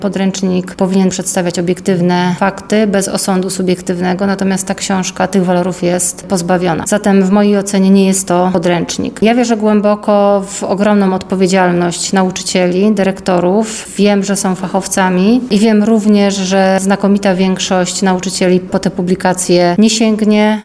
Podręcznik autorstwa prof. Wojciecha Roszkowskiego zawiera wiele kontrowersyjnych treści. Nie nazwałabym tej publikacji podręcznikiem, mówi wiceprezydent Katarzyna Kalinowska: